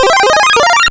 Powerup.wav